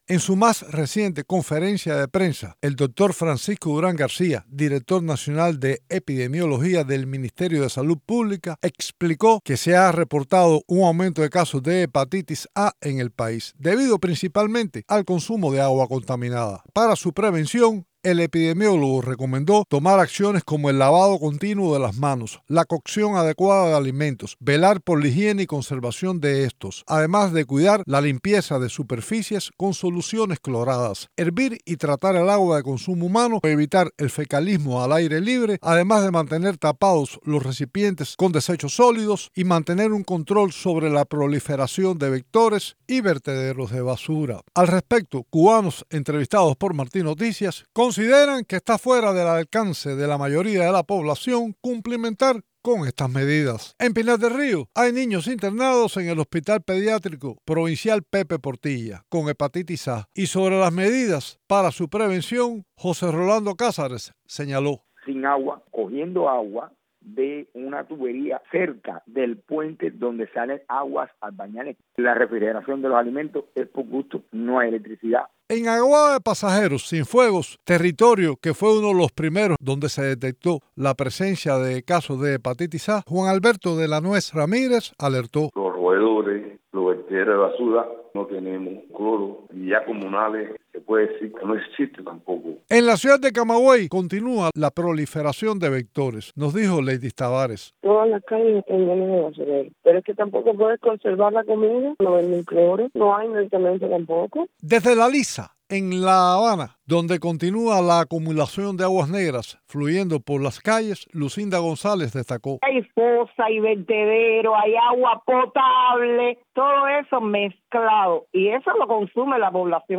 Cubanos entrevistados por Martí Noticias en varias provincias del país consideran que estas medidas preventivas están fuera del alcance de la mayoría de la población.